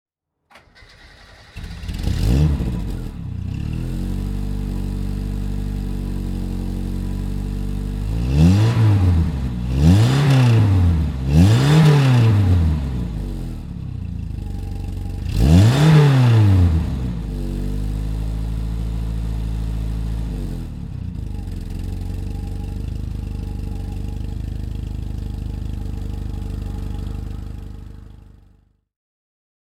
Renault 15 GTL (1977) - Starten und Leerlauf